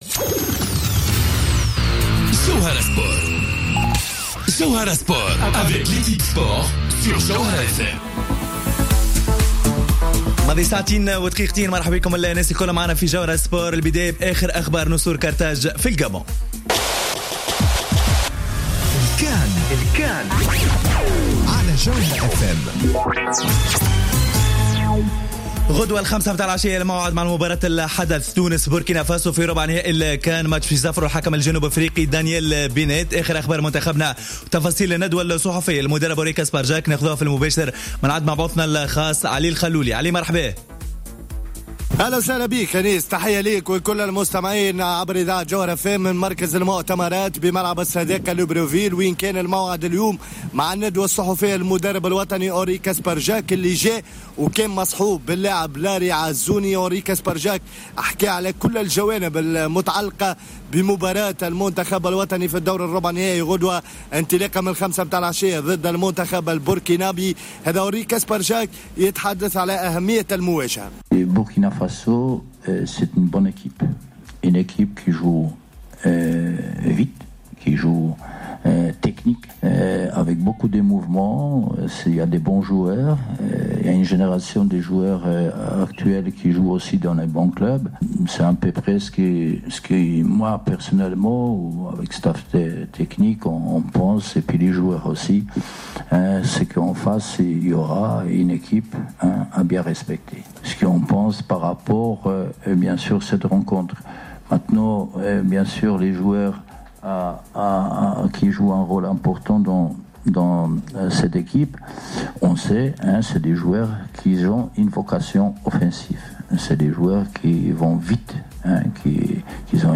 الندوة الصحفية لكسبرجاك قبل مباراة بوركينا فاسو